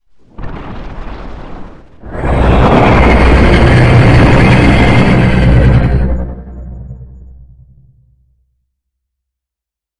跳跃的声音 " Jumpscare 4
描述：你可以用于恐怖游戏的可怕声音！
Tag: 怪物 呐喊 FNaF 恐怖 可怕